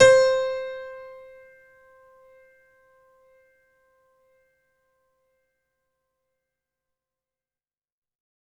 55ay-pno09-c4.wav